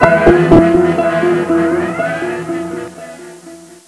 Dramatic Music Cue - 84k